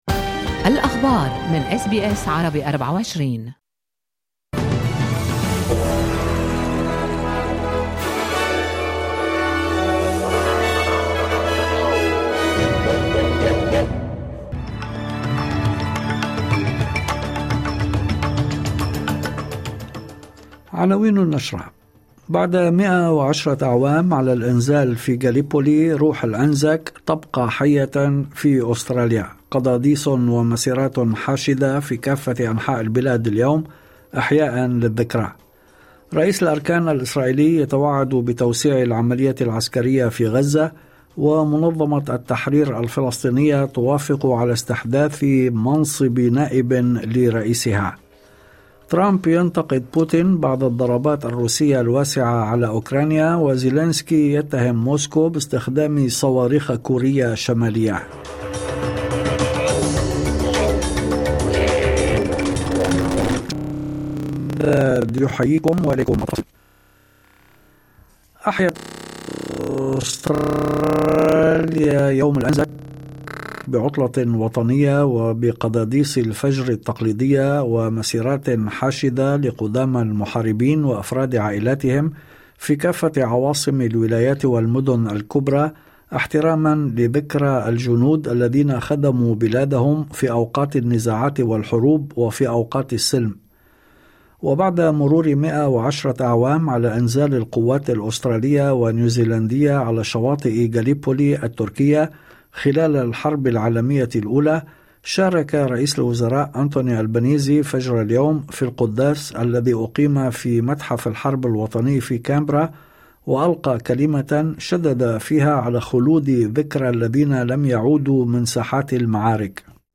نشرة أخبار المساء 25/04/2025